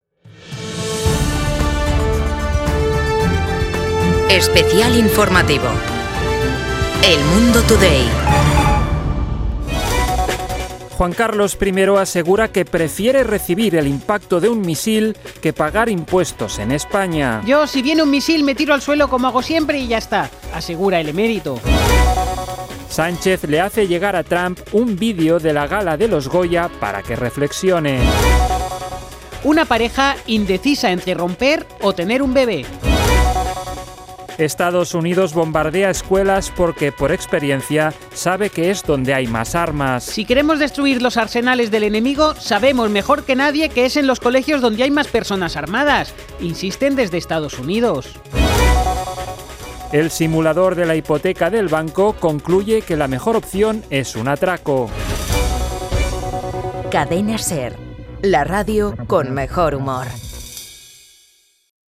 Boletín informativo de El Mundo Today | 3:00 AM